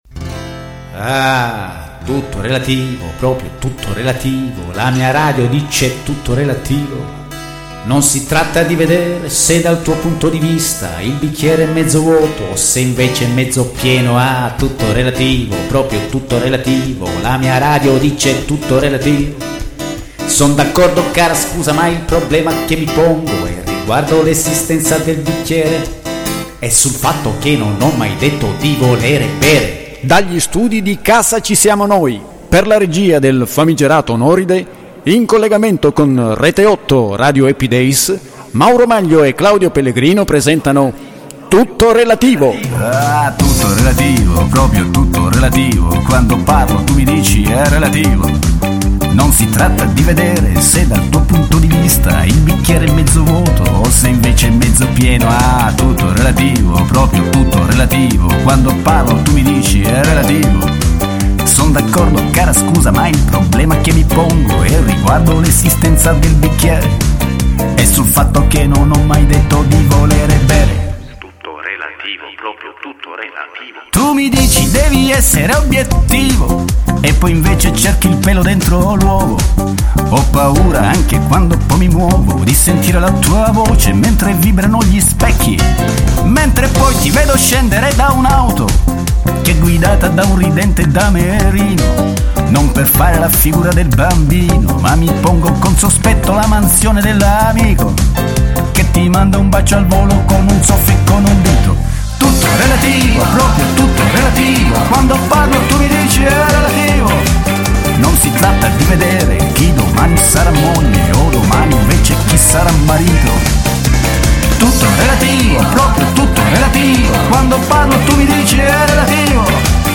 In questa pagina le interviste degli gli ospiti della trasmissione